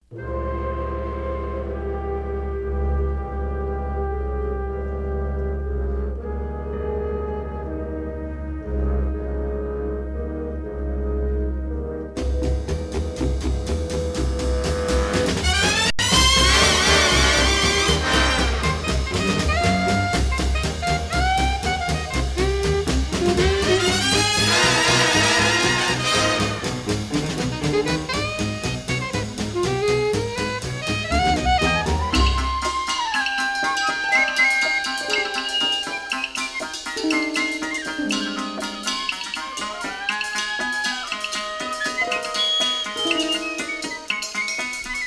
are presented in dynamic stereo